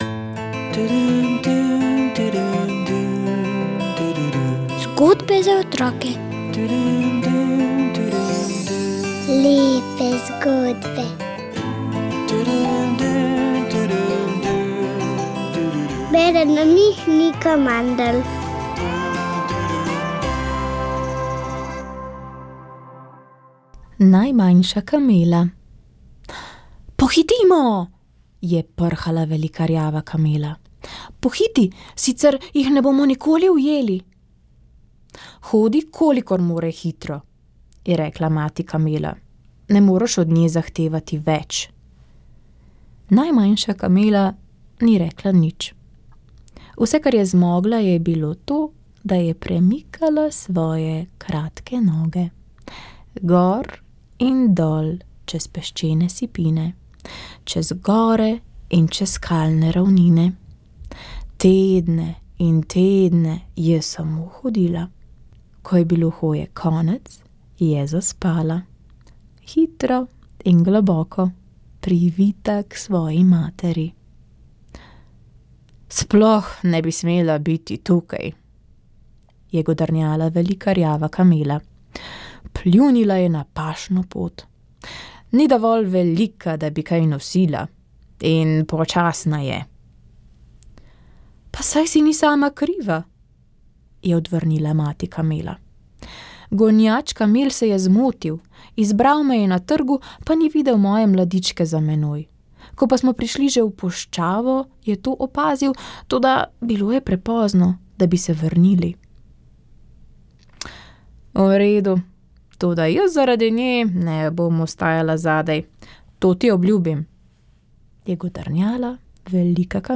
Vremenska napoved 05. junij 2023